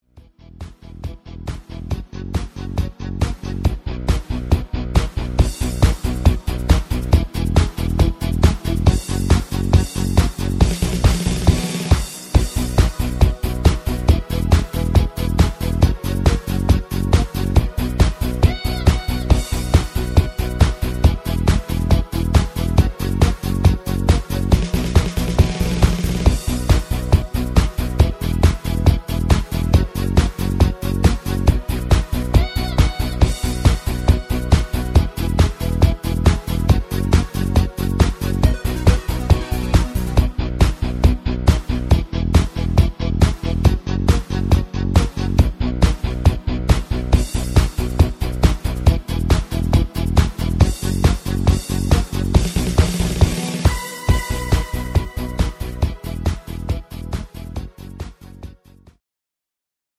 Party Mix